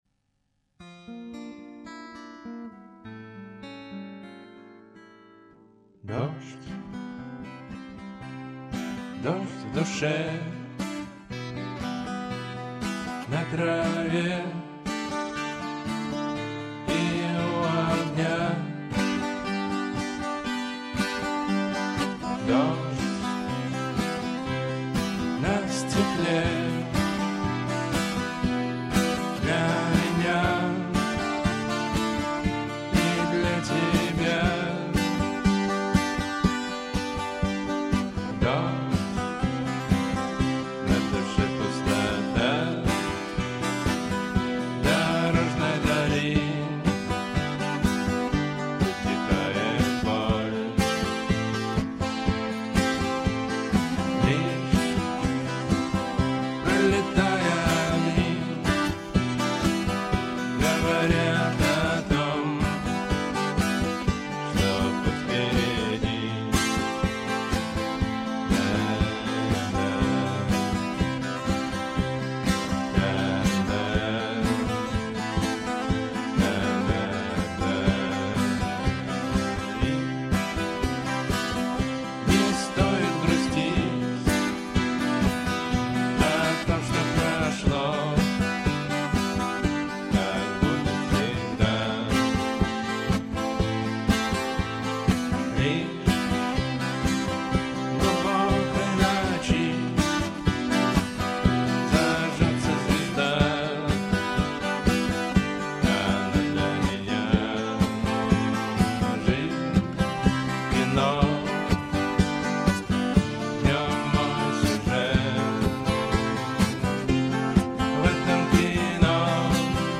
Поп Рок Авторские песни